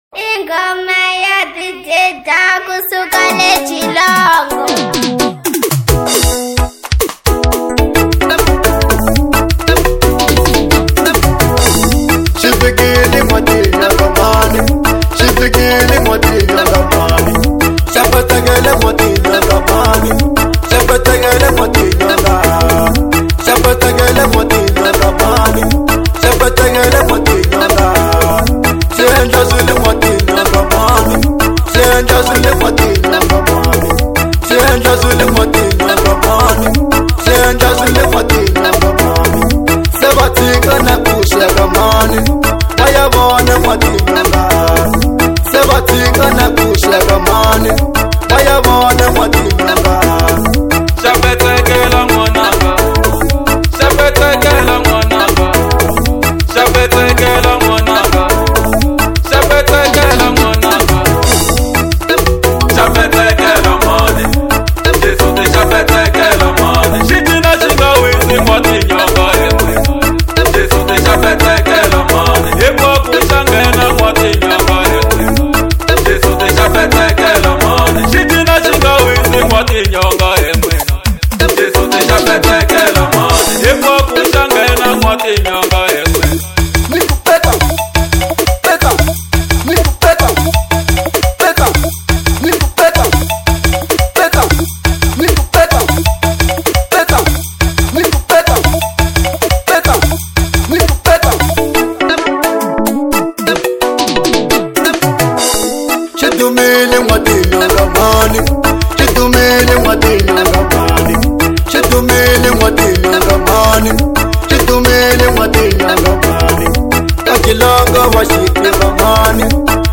04:38 Genre : Xitsonga Size